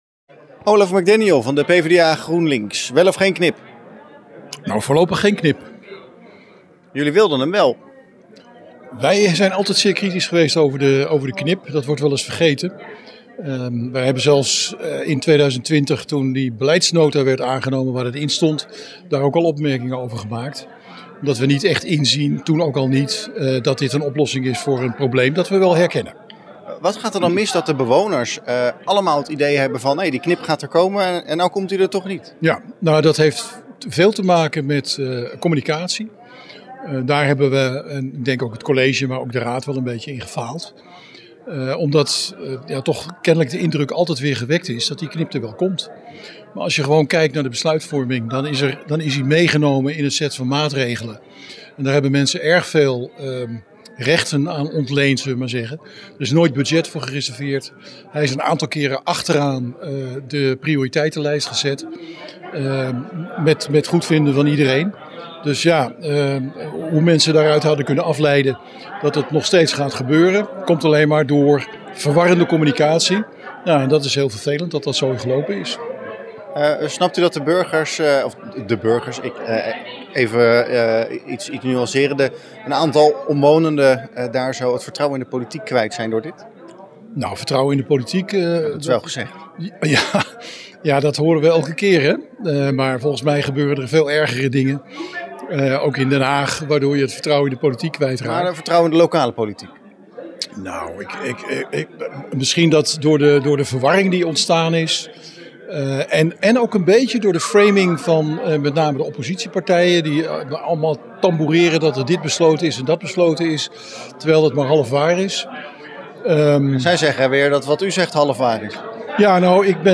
AUDIO: PvdA-raadslid Olaf McDaniel over de knip.